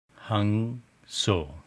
Speaker to hear sound) mantra (sound of breath!)